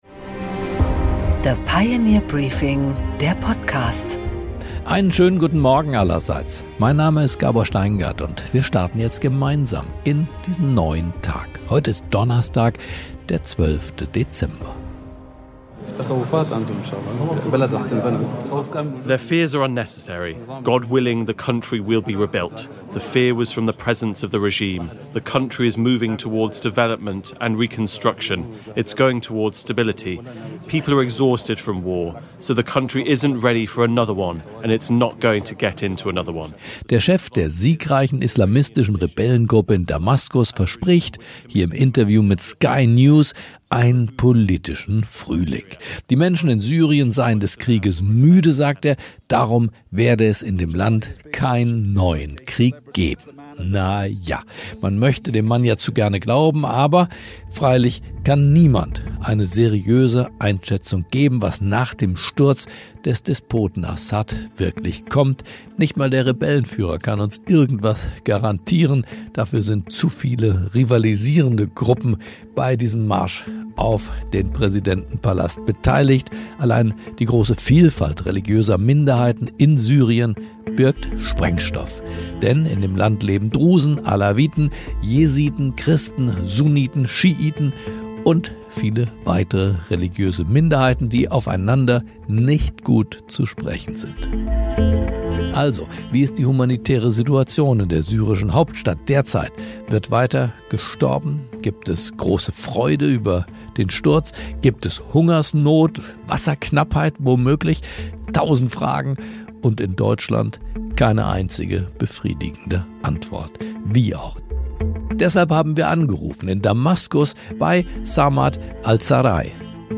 Gabor Steingart präsentiert das Pioneer Briefing
Im Interview: Sigmar Gabriel, ehemaliger SPD-Außenminister, analysiert im Gespräch mit Gabor Steingart die Geschehnisse in Syrien, die Fehler deutscher Politiker und die Frage, wer der große Gewinner der Entwicklungen ist.